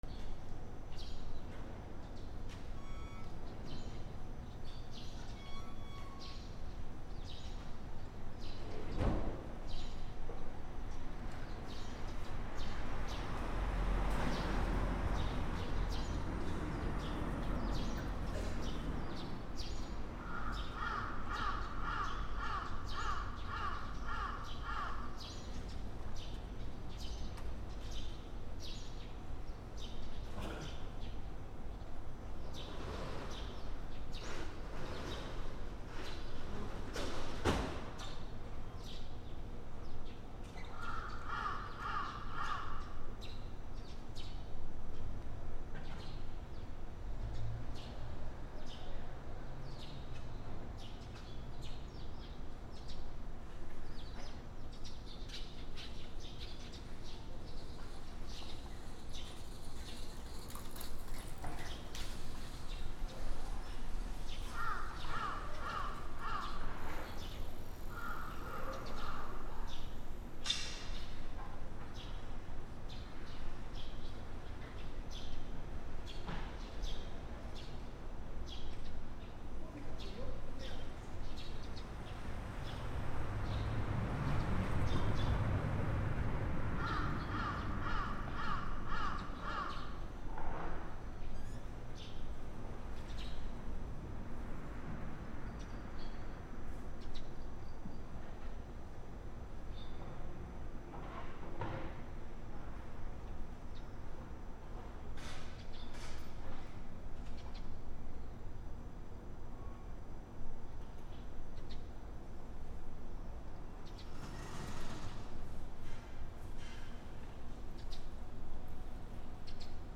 朝 住宅街 スズメ
/ D｜動物 / D-05 ｜鳥 / 10｜スズメ